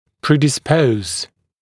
[ˌpriːdɪ’spəuz] [ˌпри:ди’споуз] предрасполагать, склонять к (чему-л.)